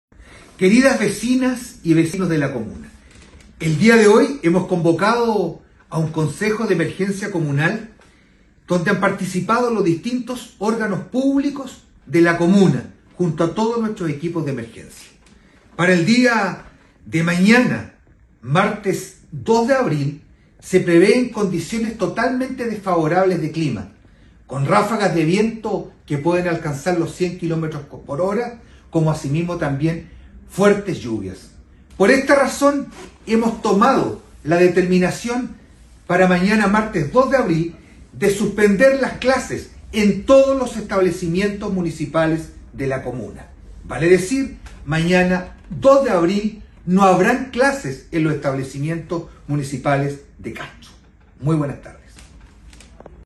Entre otras medidas, se determinó la suspensión de clases para todos los establecimientos educacionales municipales, incluyendo a los jardines infantiles VTF, tal como lo informó el alcalde y presidente de la Corporación Municipal, Juan Eduardo Vera: